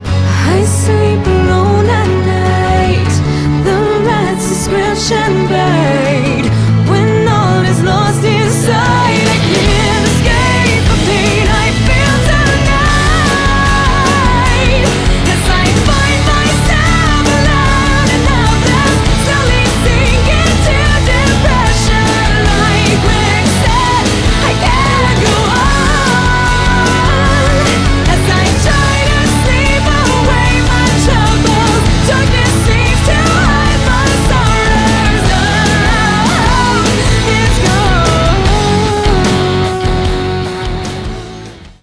ROCK
Rock/Edgy belt